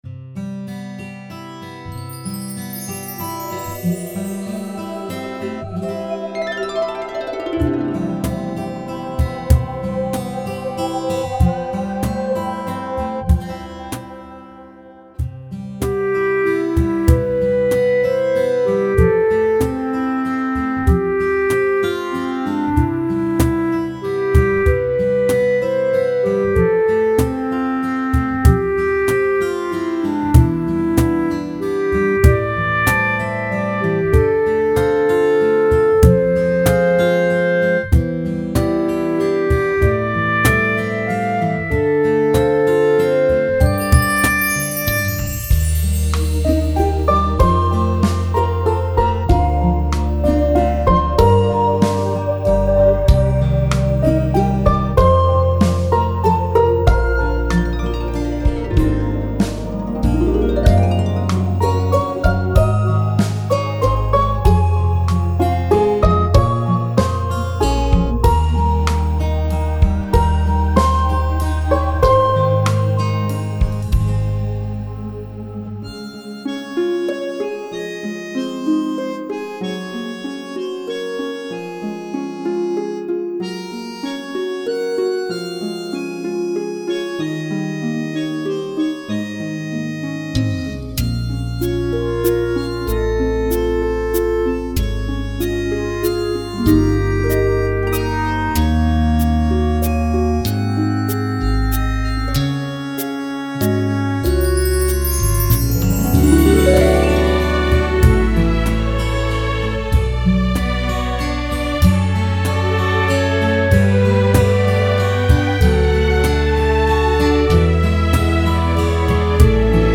宁静平和的旋律